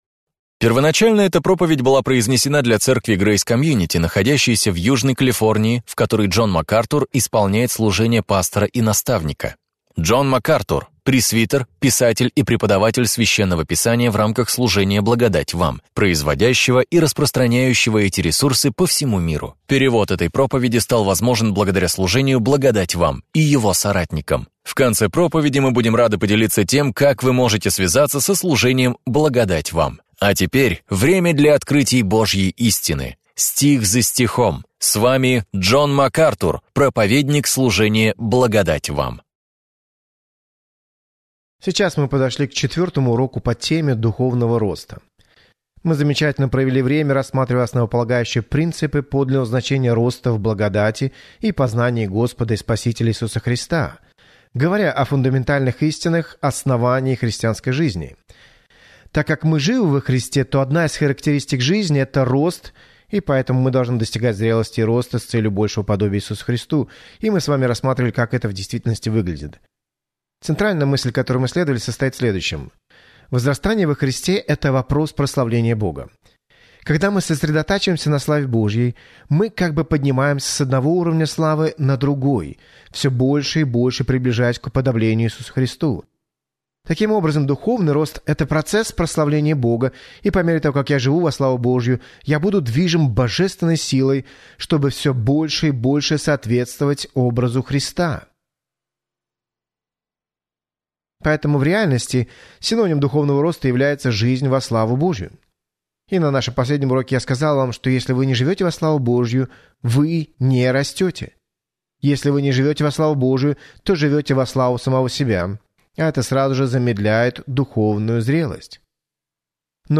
В своей проповеди «Возвращение к основам», актуальной для всякого времени, Джон Макартур делает обзор базовых принципов христианства и помогает вам сделать их основанием для своей жизни.